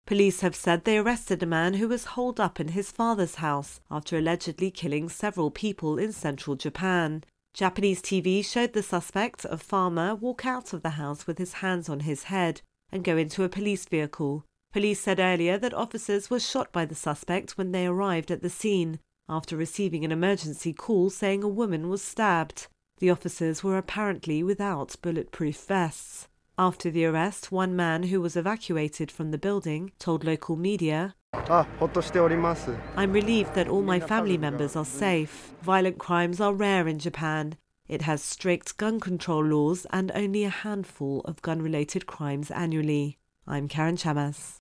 reports on Japan shooting.